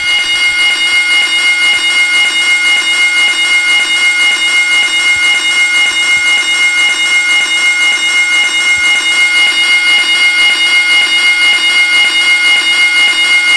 FireAlarm.wav